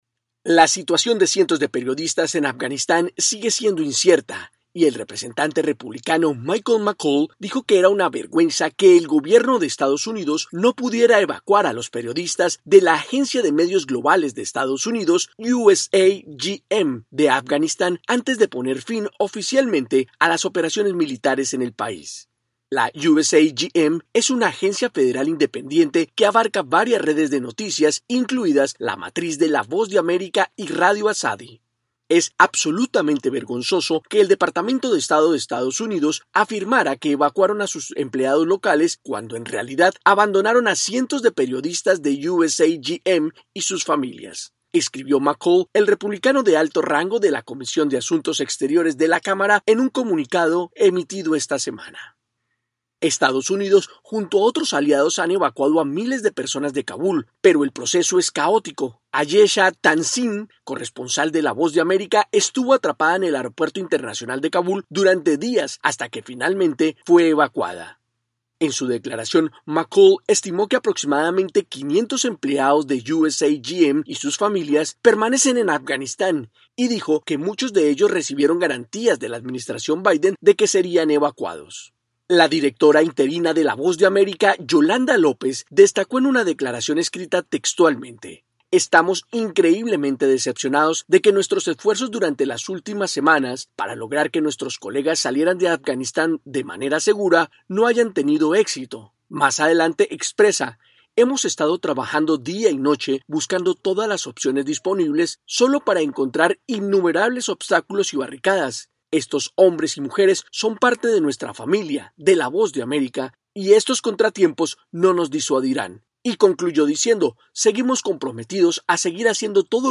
AudioNoticias
desde la Voz de América en Washington, DC.